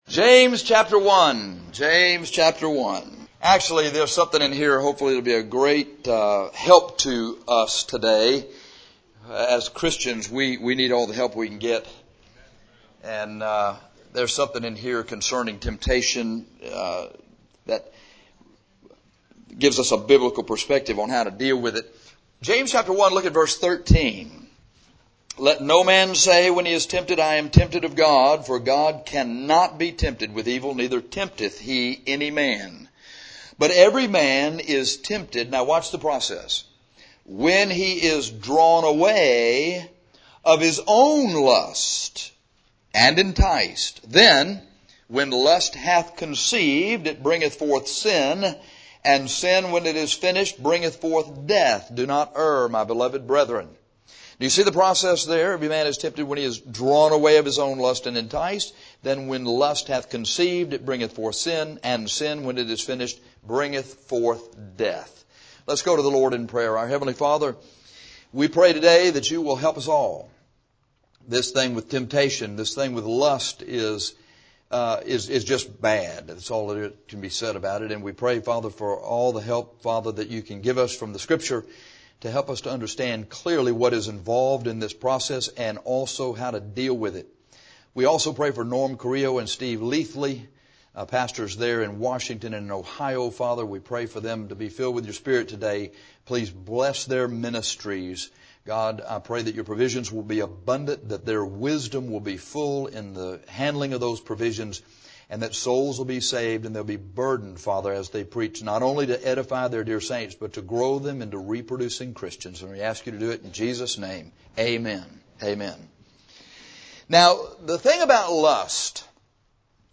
This sermon is on how to defend against lust.